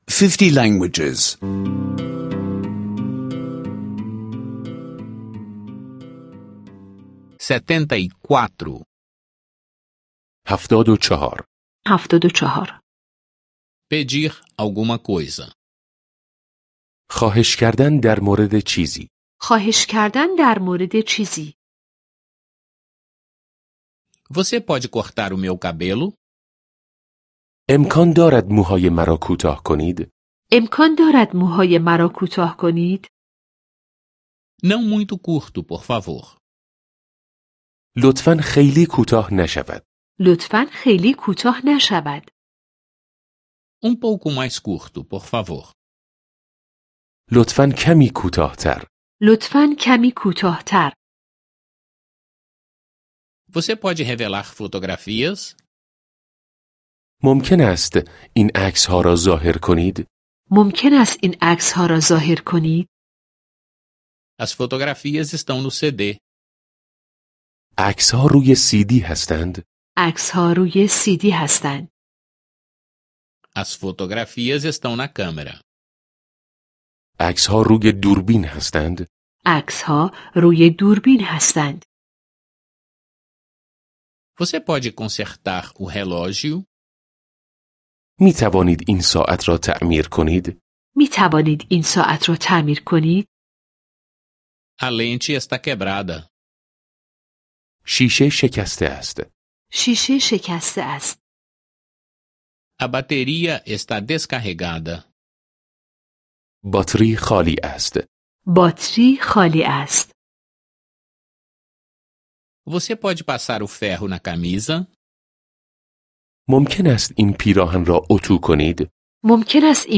Aulas de persa em áudio — download grátis